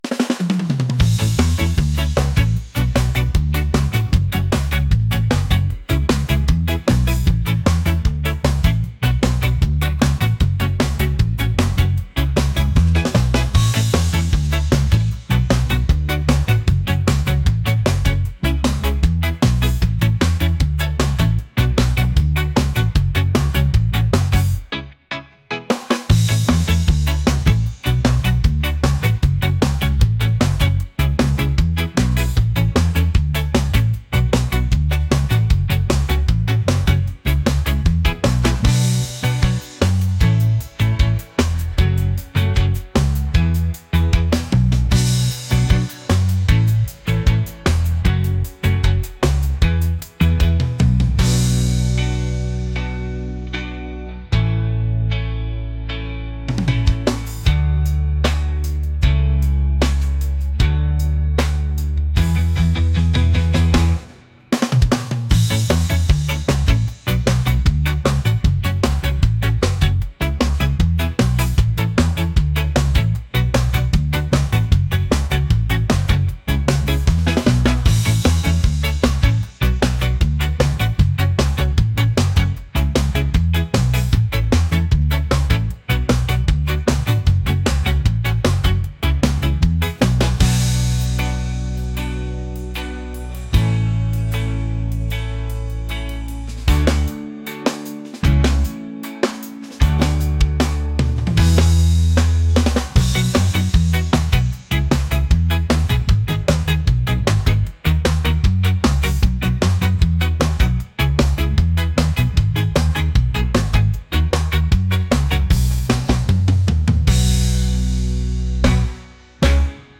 reggae | upbeat | catchy